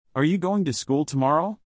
For yes or no questions, use a rising intonation at the end of the sentence.